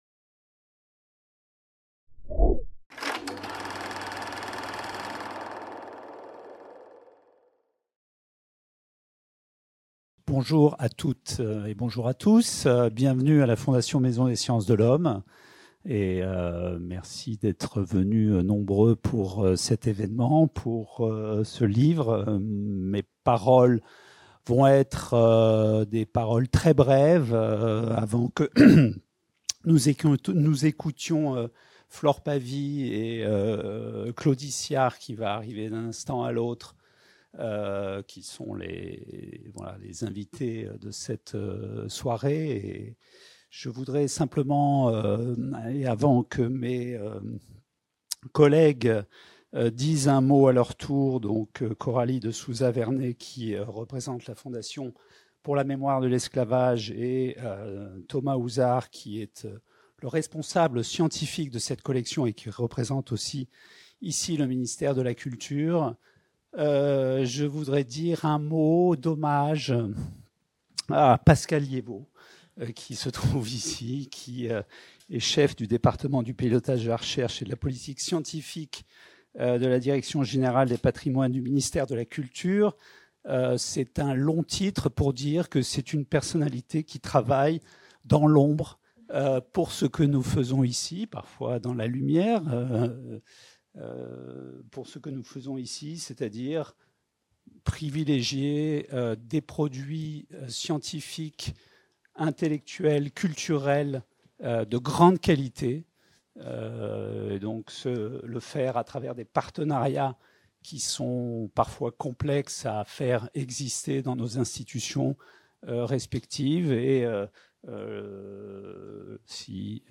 qui s'est tenue le 27 mai au Forum de la FMSH